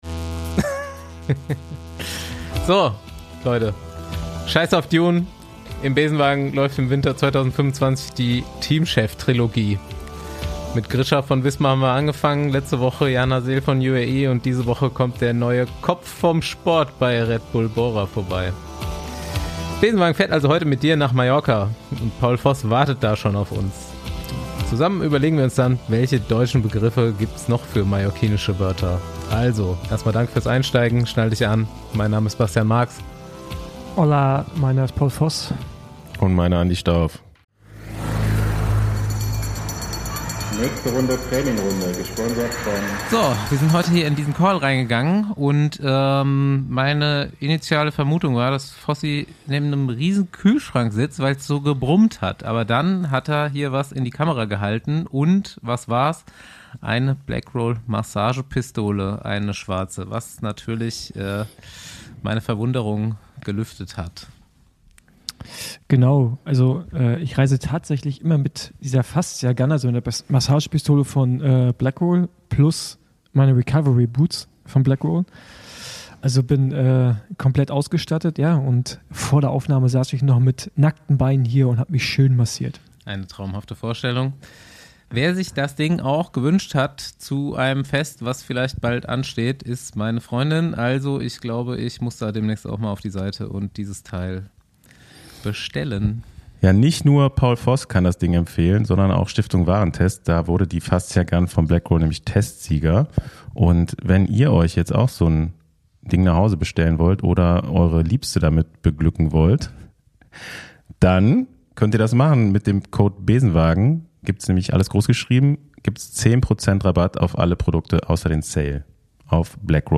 Dezember '25 Der Besenwagen rollt ins katalanische Städtchen Olot.